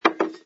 sfx_put_down_bottle08.wav